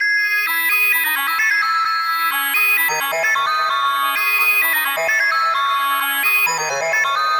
MB - Loop 9 - 65BPM.wav